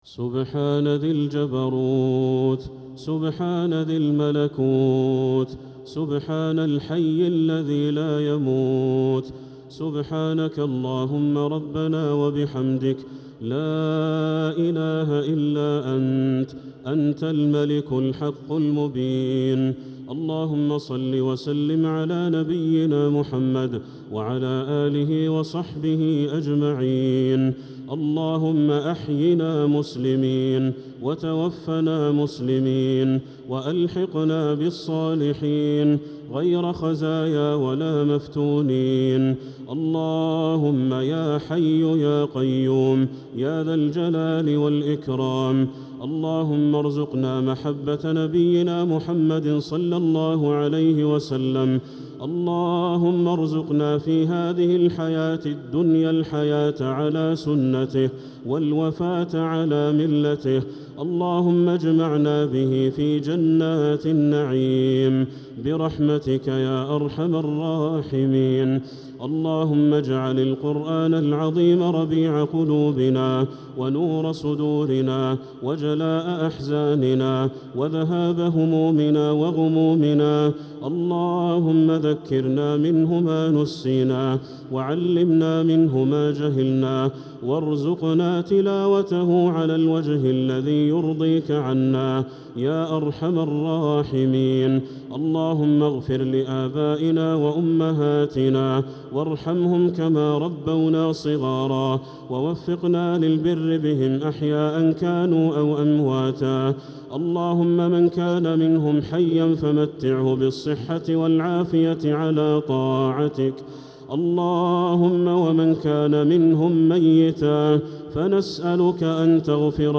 دعاء القنوت ليلة 12 رمضان 1446هـ | Dua for the night of 12 Ramadan 1446H > تراويح الحرم المكي عام 1446 🕋 > التراويح - تلاوات الحرمين